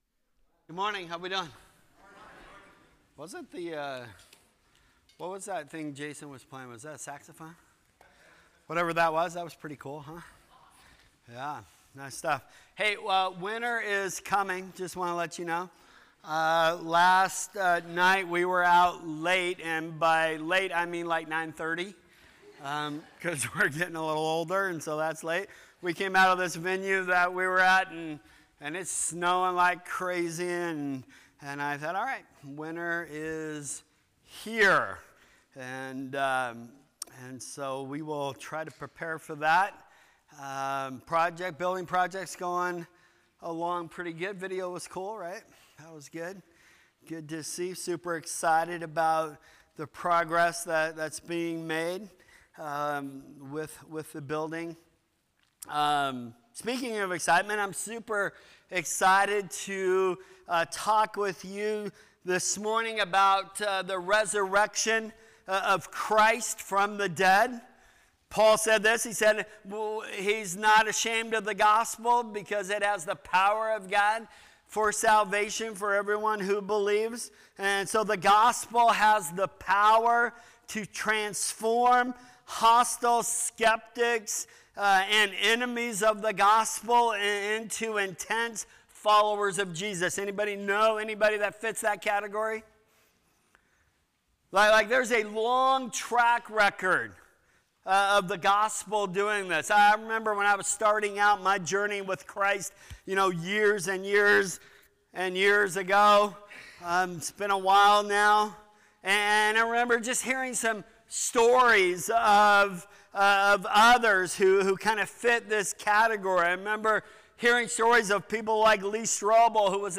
Sermons | New Creation Fellowship